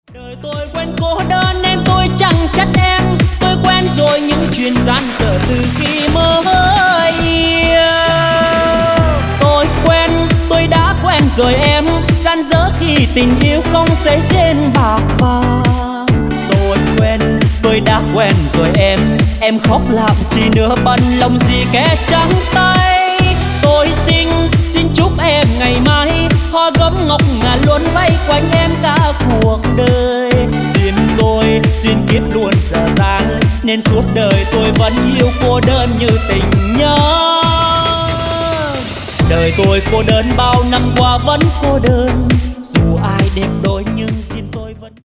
EDM/ Underground